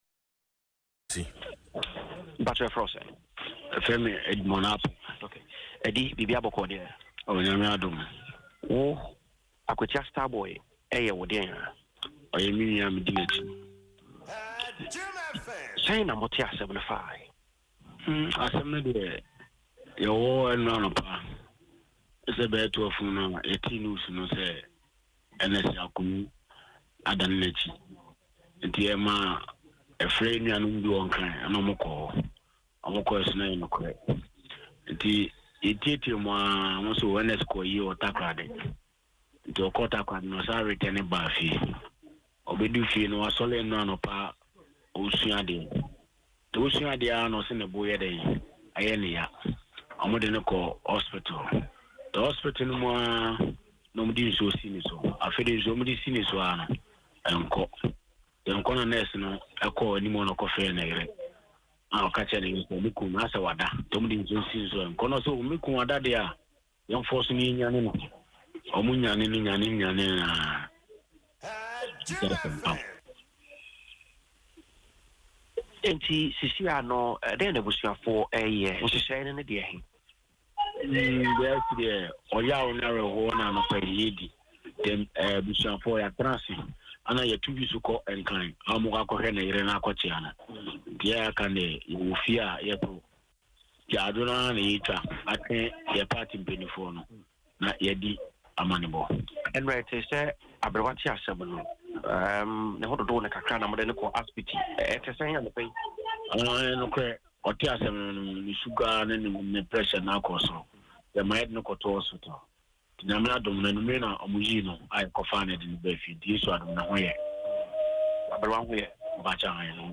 In an interview on Adom FM’s Dwaso Nsem, relatives from his hometown of Wenchi in the Eastern Region described his death as a major blow—not just to them, but to the New Patriotic Party (NPP) and the entire nation.